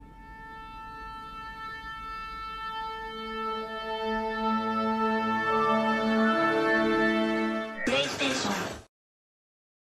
Download PlayStation Sound effect Button free on sound buttons.